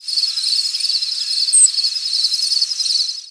Descending seeps
Saltmarsh Sharp-tailed Sparrow
The "descending seep" group is a large species complex in eastern North America whose flight calls are above 6KHz in frequency and are descending in pitch.